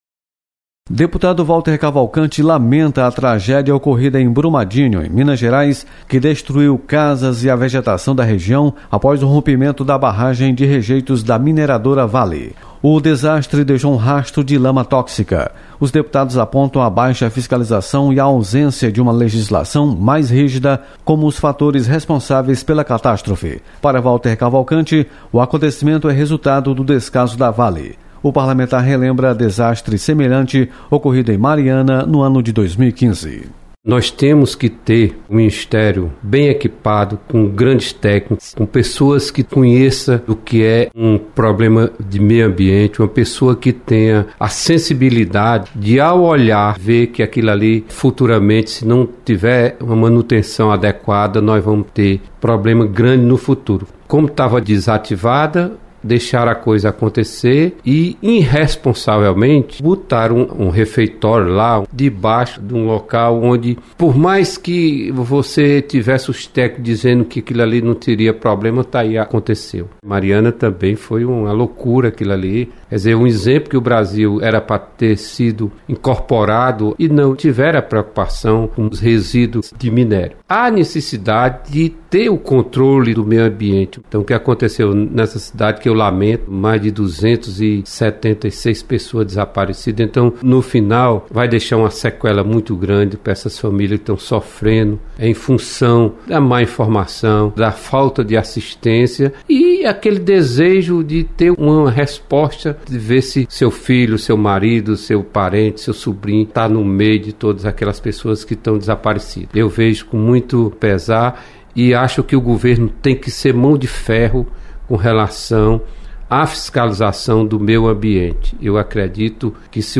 Deputado Walter Cavalcante comenta tragédia em Minas Gerais.